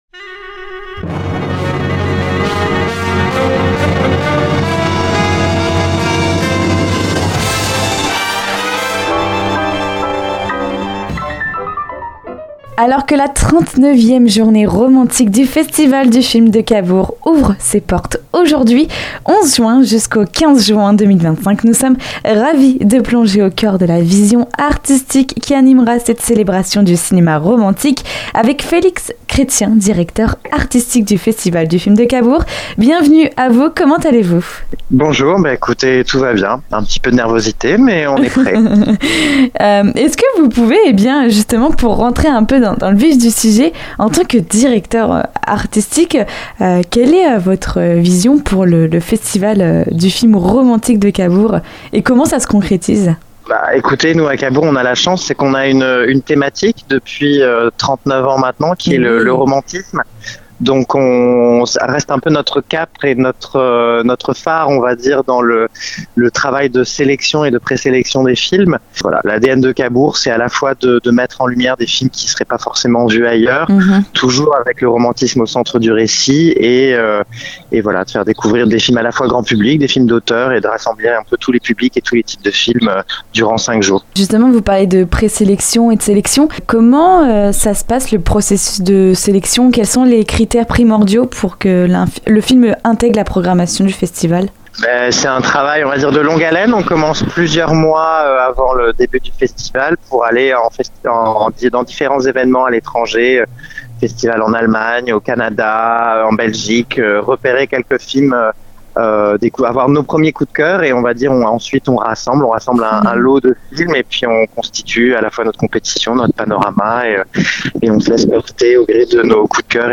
Pour en savoir plus sur cet rencontre n'hésitez pas à écouter jusqu'au bout l'interview.